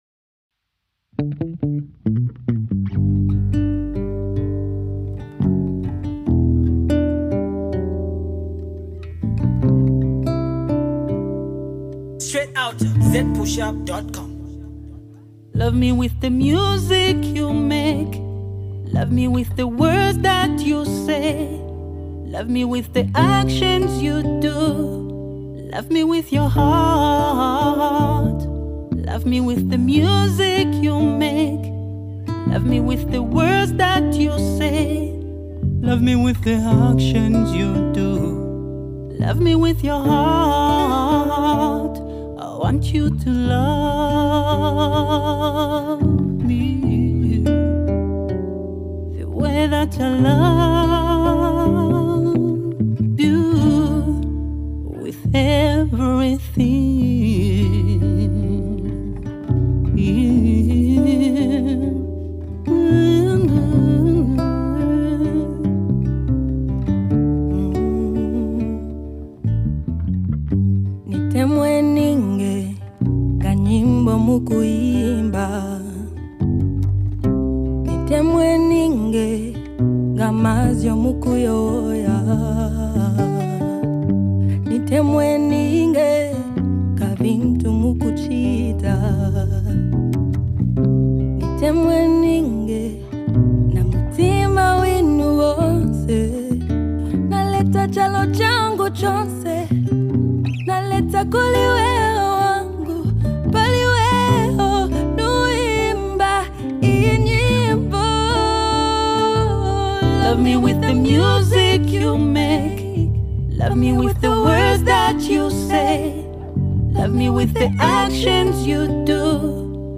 smooth witty love song